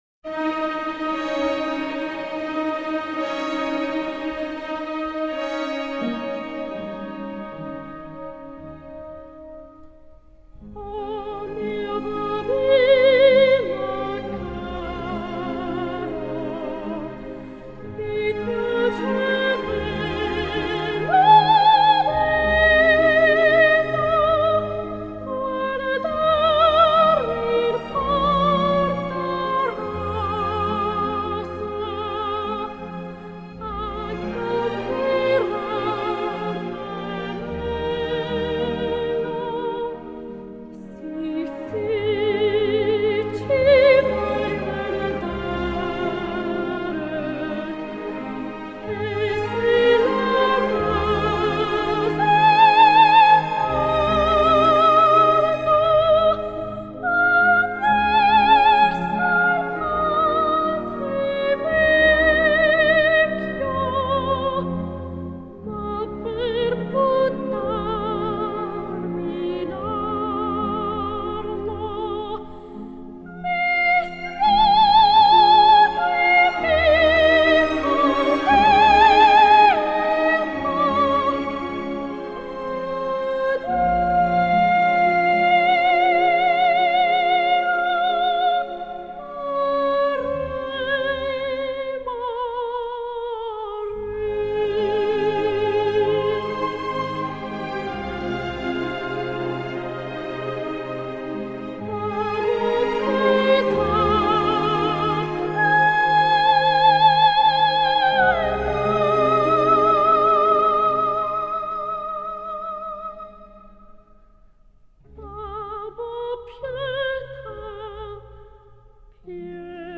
美声吟唱
音樂類型：古典音樂